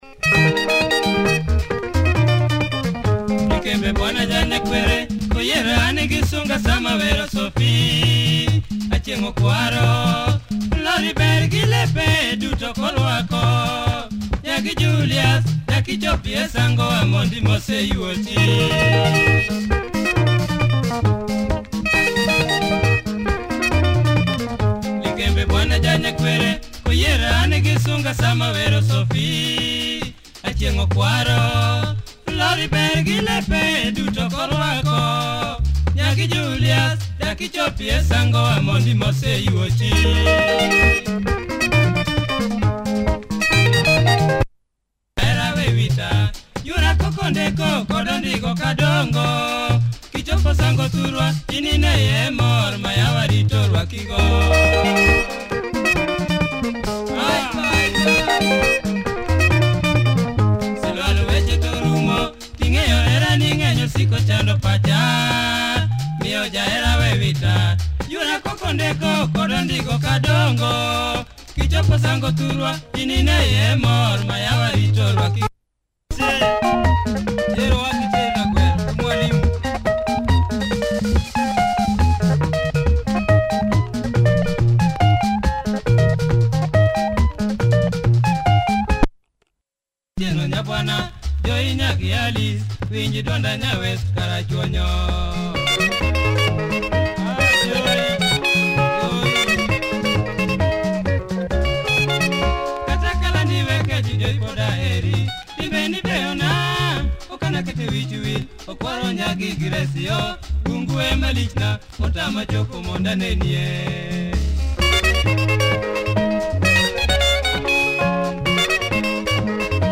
LUO benga